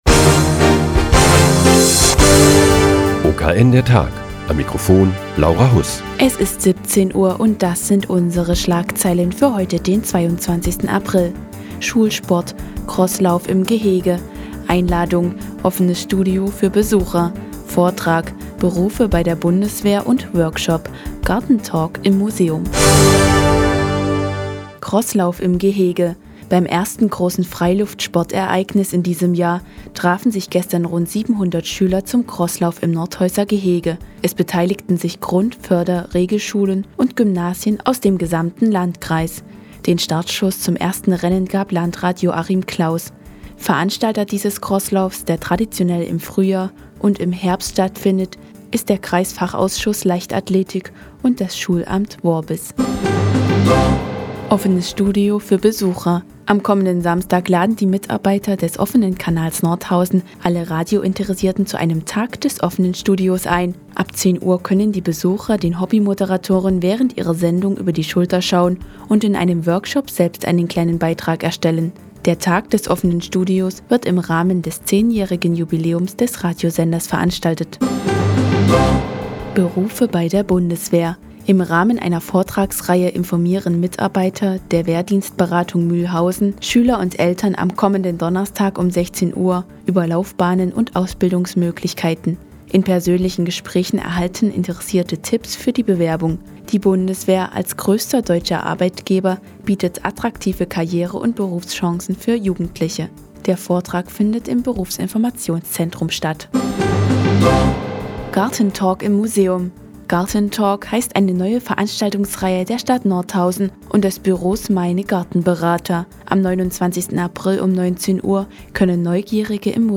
Die tägliche Nachrichtensendung des OKN ist nun auch in der nnz zu hören. Heute geht es um Karriere- und Berufschancen bei der Bundeswehr und die neue Veranstaltungsreihe "Garten-Talk".